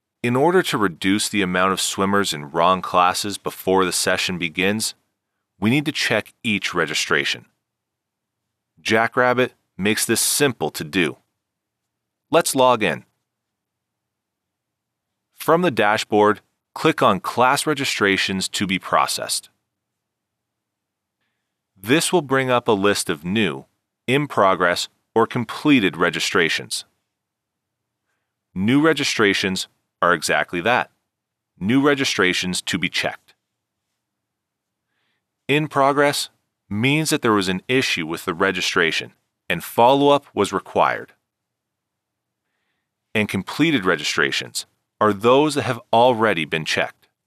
Website coporate narration.mp3